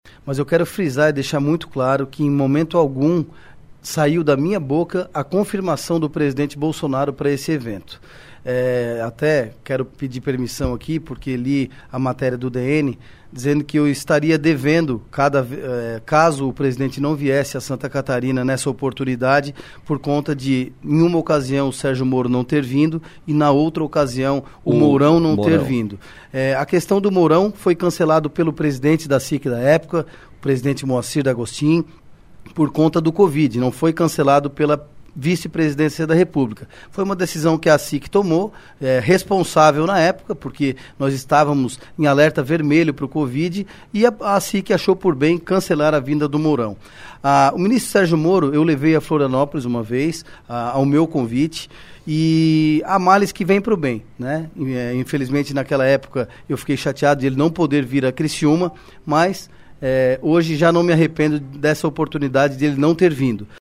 ÁUDIO: Deputado diz que nunca prometeu visita de Bolsonaro, mas que segue tentando